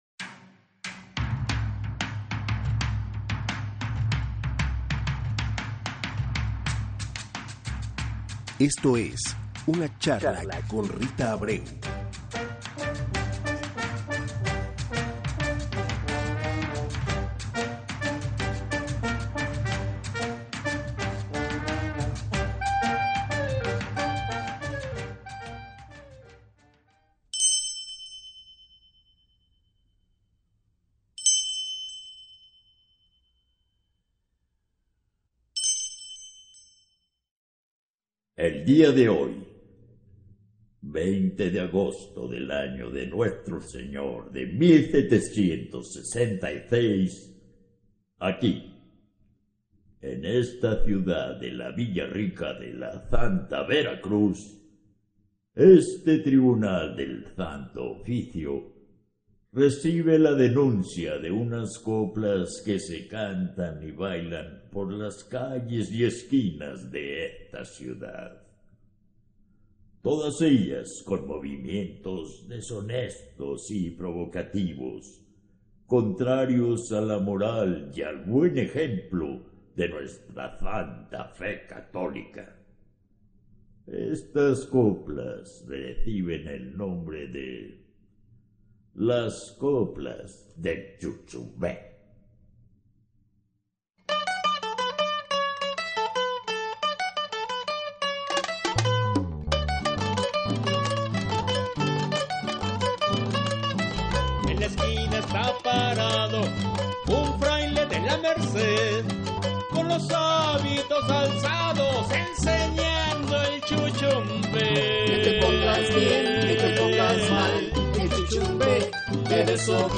Escucha la entrevista Nesh-Kalha